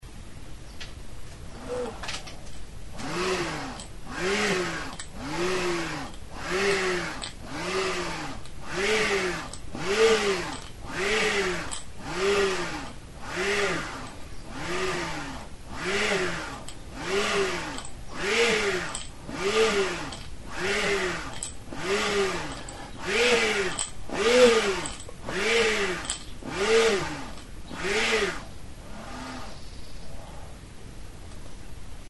Music instrumentsFIRRINGILA
Aerophones -> Free-vibrating
Recorded with this music instrument.
Zurezko oholtxo bat da. Erdian bi zulotxo ditu eta horietatik sokatxo bat pasatzen da.
WOOD